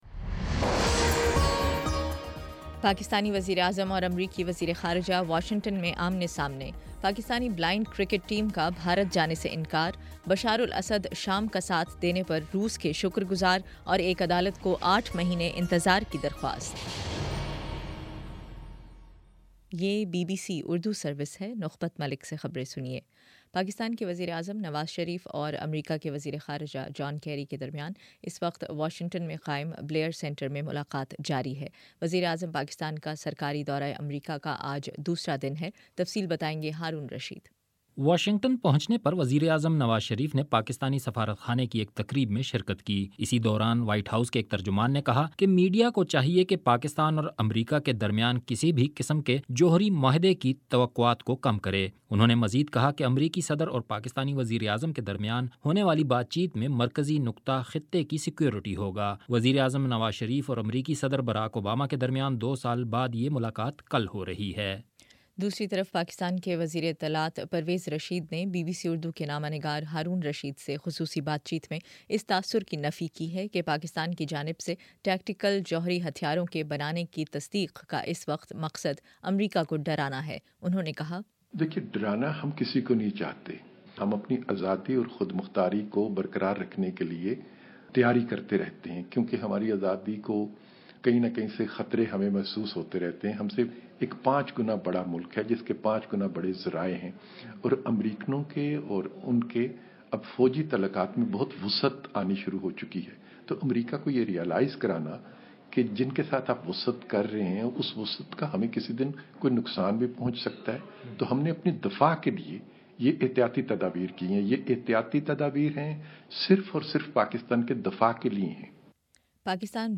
اکتوبر 21 : شام سات بجے کا نیوز بُلیٹن